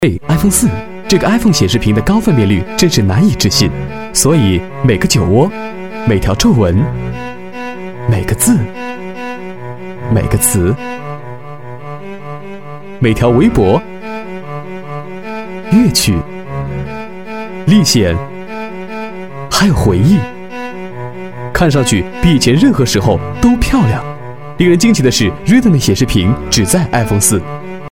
广告男19号A(苹果手机）
年轻时尚 品牌广告